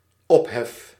Ääntäminen
Synonyymit rumeur Ääntäminen FR: IPA: [bru.a.a] FR: IPA: /bʁu.a.a/ Haettu sana löytyi näillä lähdekielillä: ranska Käännös Ääninäyte 1. geluid {n} 2. herrie {f} 3. drukte {f} 4. ophef {m} 5. lawaai {n} Suku: m .